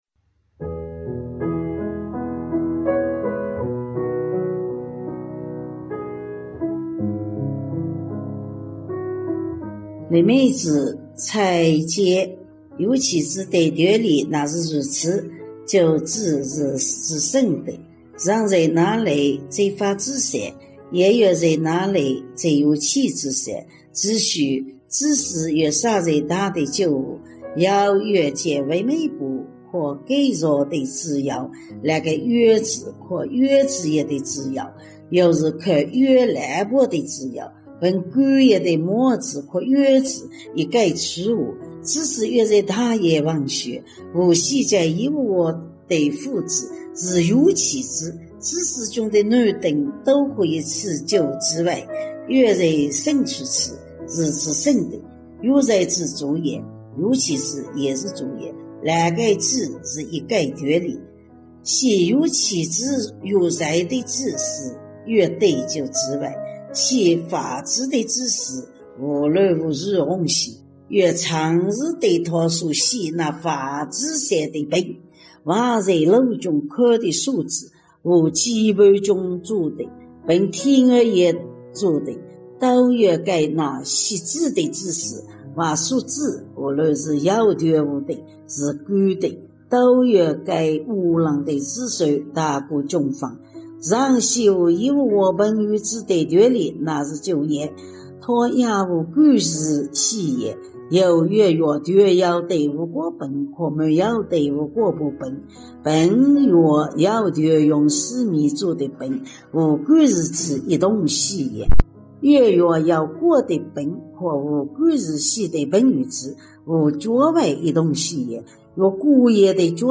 语言：温州话